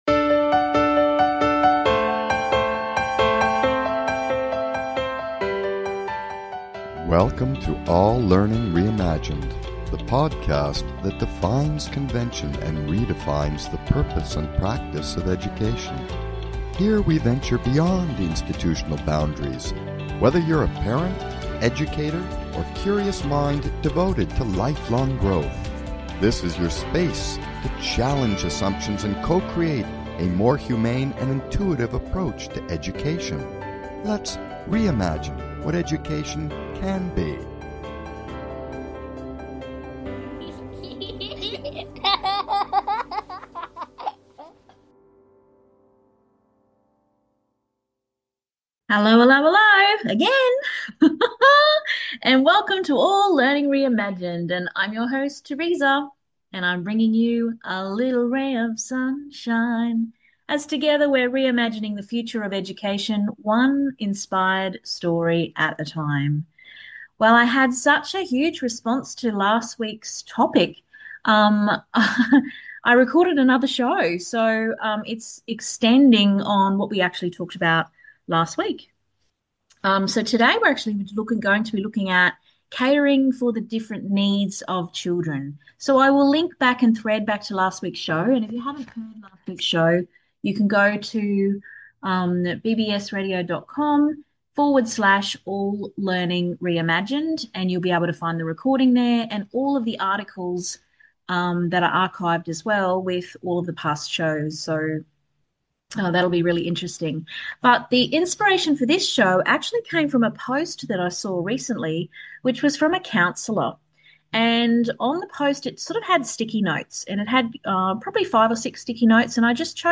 Talk Show Episode, Audio Podcast, All Learning Reimagined and Catering for the needs of Children on , show guests , about Catering for the needs of Children, categorized as Education,Entertainment,Kids & Family,Music,Philosophy,Psychology,Self Help,Society and Culture,Variety